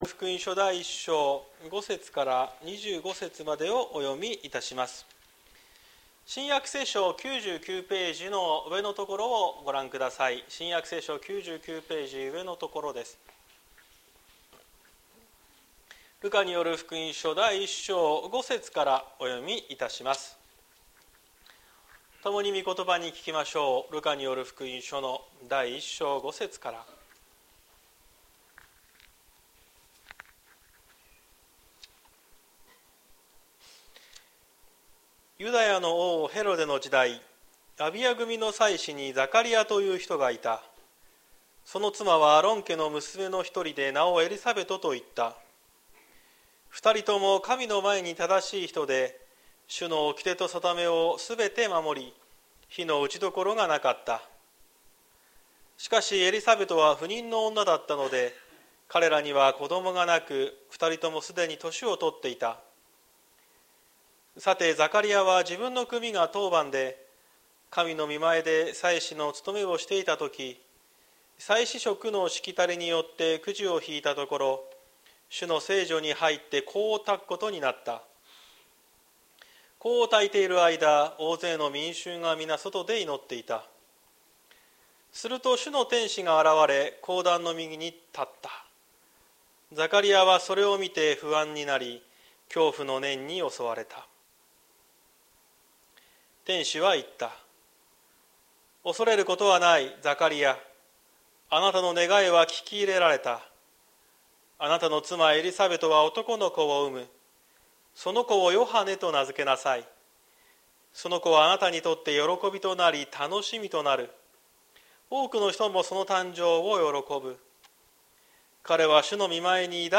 2024年12月01日朝の礼拝「不安の先に」綱島教会
説教アーカイブ。